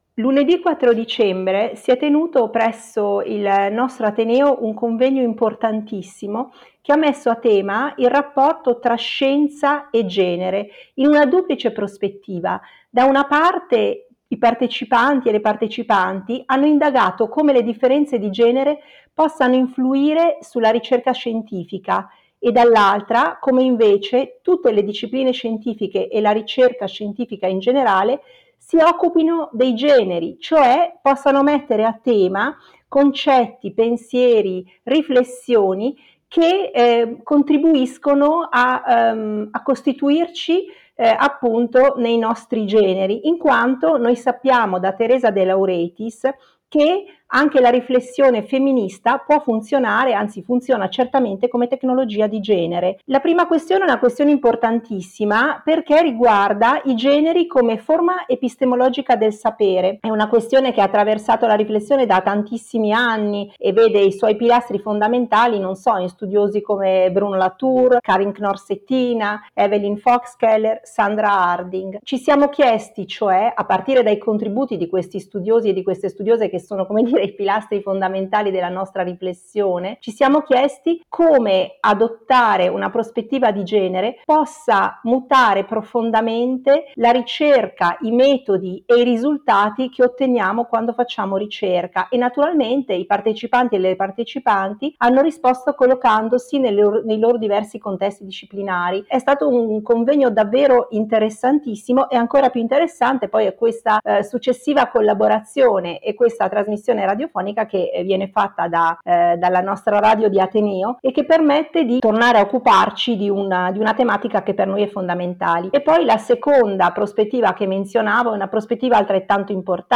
Speciale radio “I generi nella scienza e la scienza dei generi”